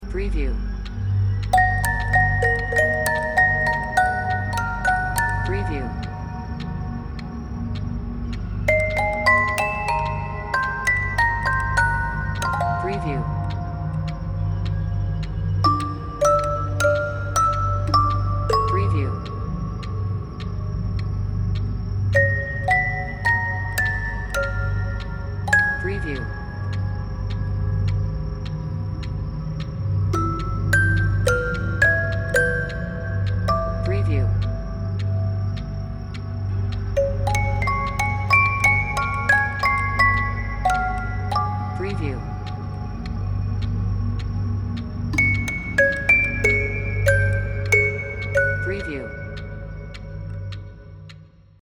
Musicbox Horror SFX.mp3